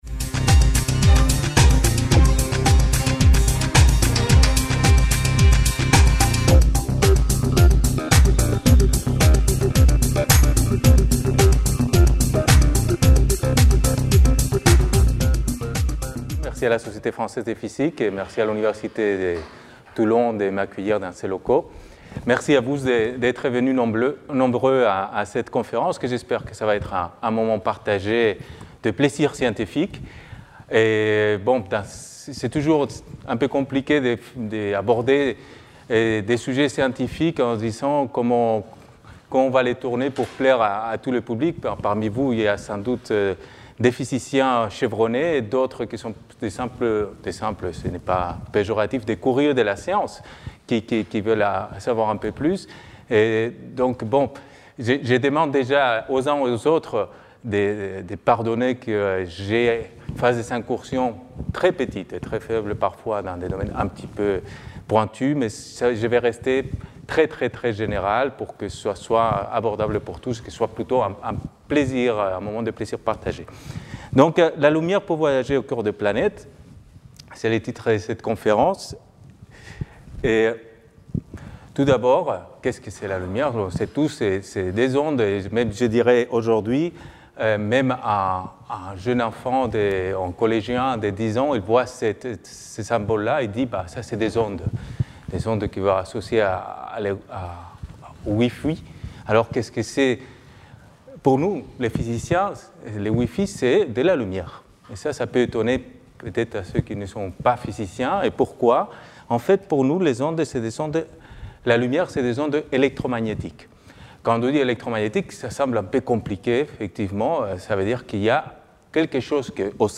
Conférence grand public destinée aux esprits curieux !